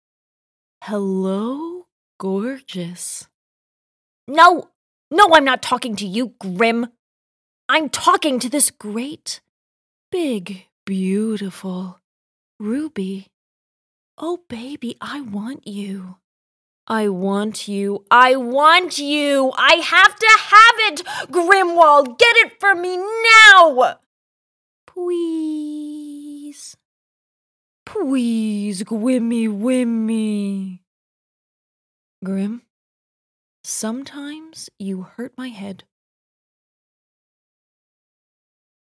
hell, fein, zart, sehr variabel
Game